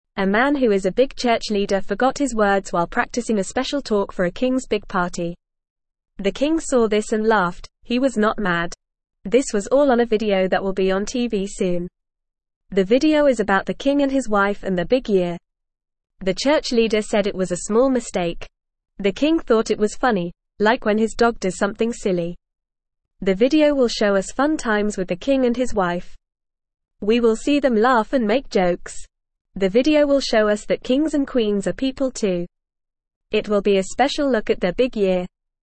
Fast
English-Newsroom-Beginner-FAST-Reading-The-Kings-Funny-Video-A-Special-Look.mp3